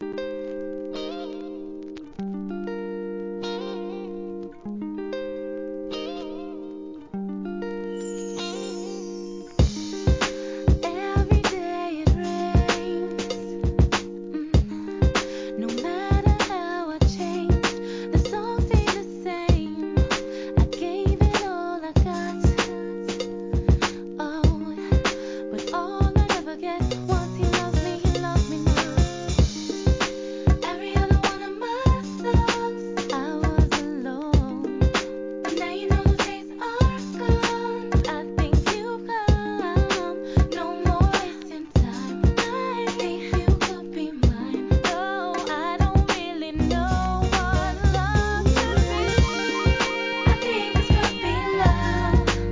HIP HOP/R&B
哀愁美メロの